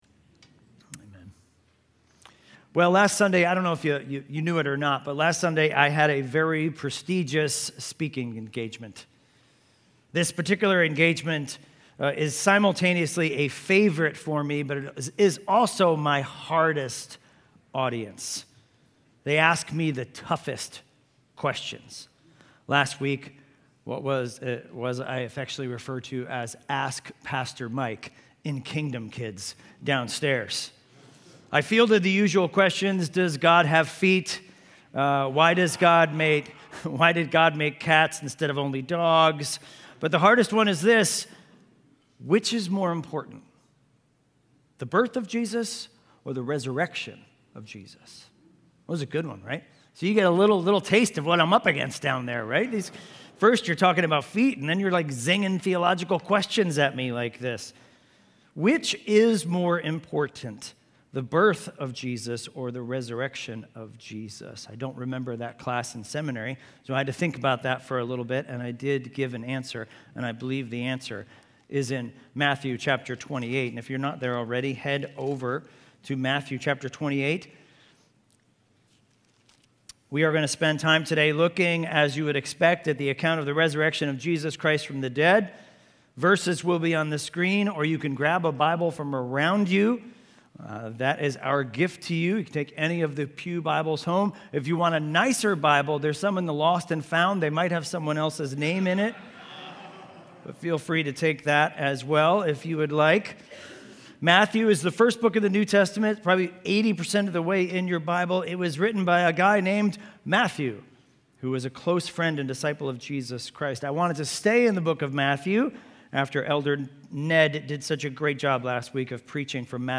Weekly Sunday AM sermon series in Genesis.